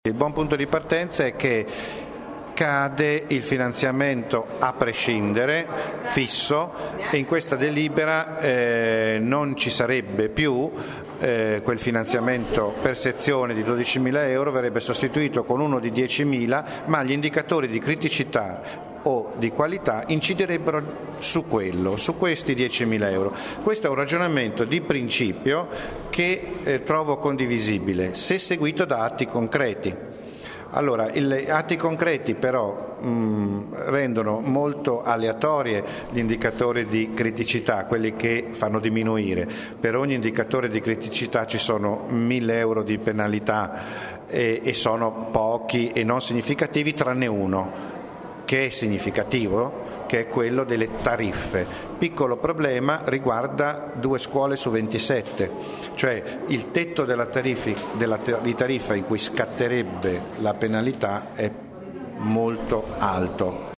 Ascolta Mirco Pieralisi (Sel), presidente della commissione Istruzione: